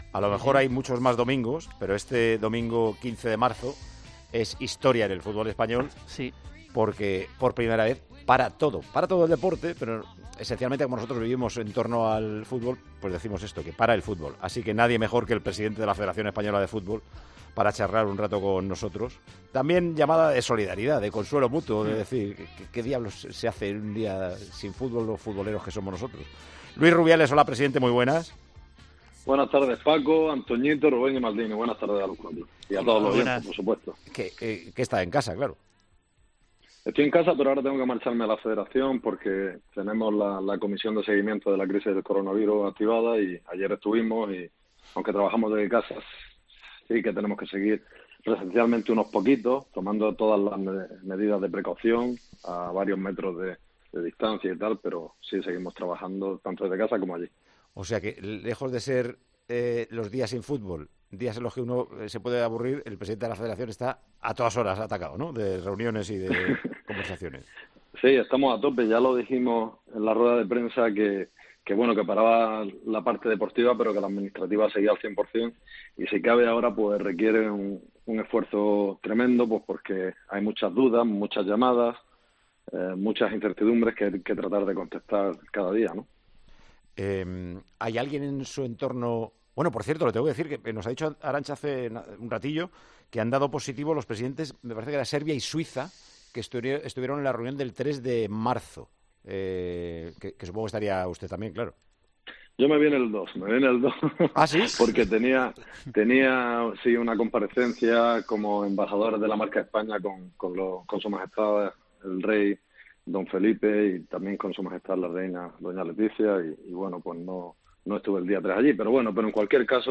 AUDIO: El presidente de la RFEF aseguró en Tiempo de Juego que muy pronto se sabrá que ocurrirá en el fútbol y en el fútbol sala.